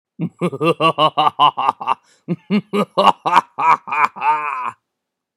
maniacal laugh
English Evil Laugh sound effect free sound royalty free Funny